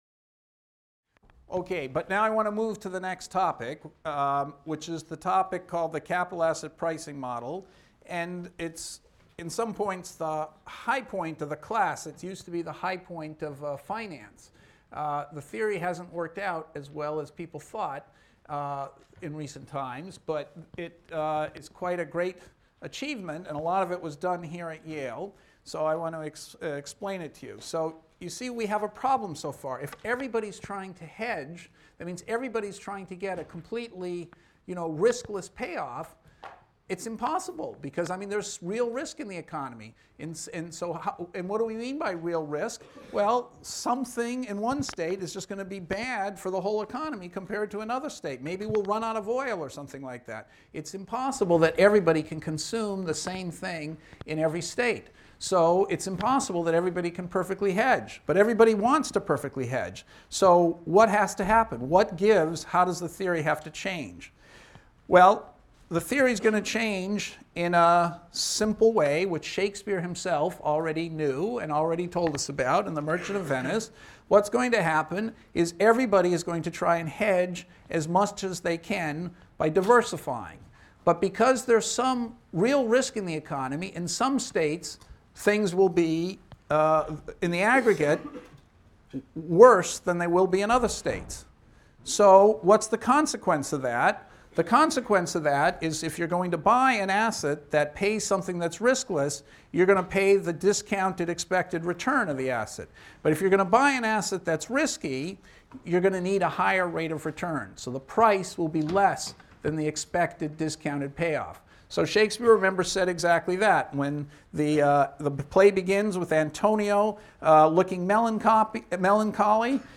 ECON 251 - Lecture 22 - Risk Aversion and the Capital Asset Pricing Theorem | Open Yale Courses